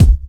Clear Bass Drum One Shot C Key 376.wav
Royality free kick drum one shot tuned to the C note. Loudest frequency: 245Hz
.WAV .MP3 .OGG 0:00 / 0:01 Type Wav Duration 0:01 Size 48,58 KB Samplerate 44100 Hz Bitdepth 32 Channels Mono Royality free kick drum one shot tuned to the C note.
clear-bass-drum-one-shot-c-key-376-AR5.ogg